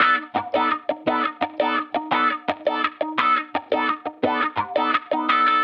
Index of /musicradar/sampled-funk-soul-samples/85bpm/Guitar
SSF_StratGuitarProc1_85B.wav